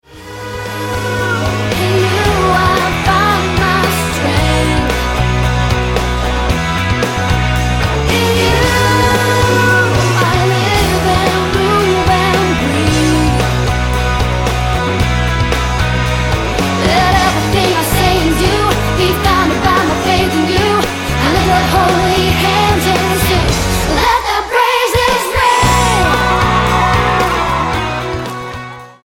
STYLE: Childrens